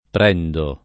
vai all'elenco alfabetico delle voci ingrandisci il carattere 100% rimpicciolisci il carattere stampa invia tramite posta elettronica codividi su Facebook prendere [ pr $ ndere ] v.; prendo [ pr $ ndo ] — pass. rem. presi [ pr %S i ]; part. pass. preso [ pr %S o ] — cfr. pigliare